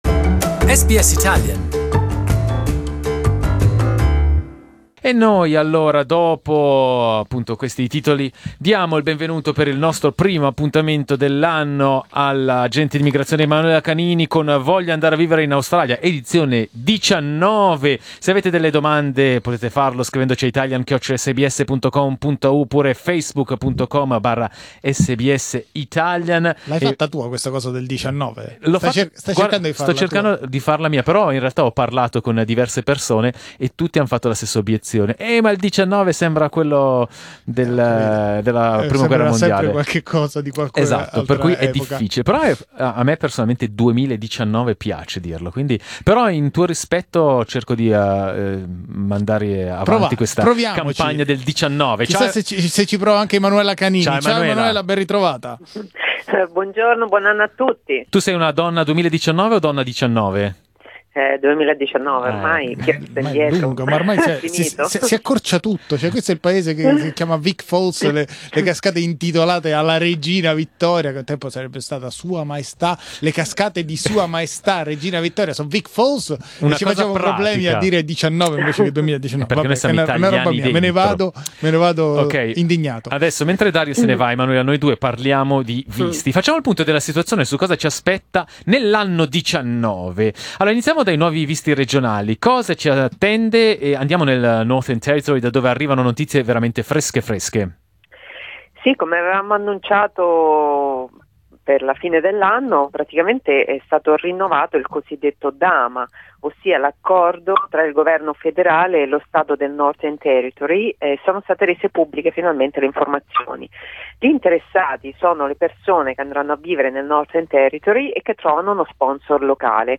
Inizia un nuovo anno e prendono il via le nuove leggi decise nei mesi scorsi per regolare l’immigrazione Down Under. Facciamo il punto della situazione con l’agente di immigrazione